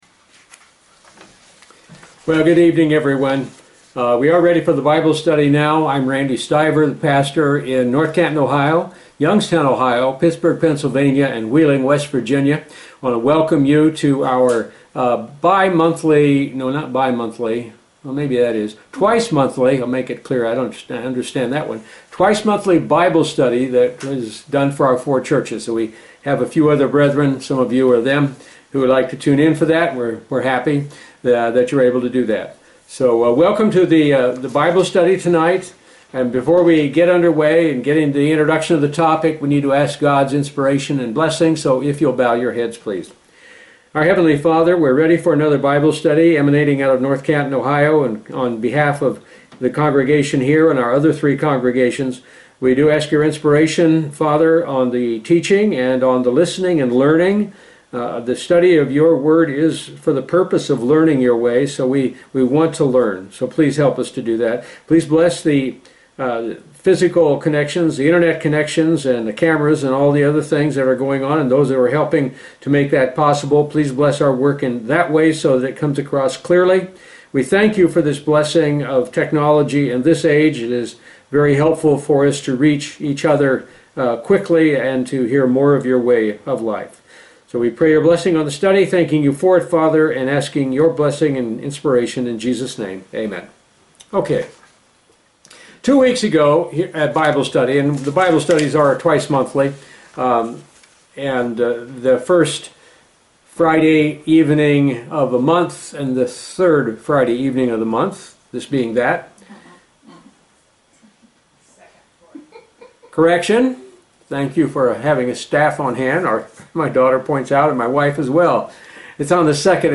Given in North Canton, OH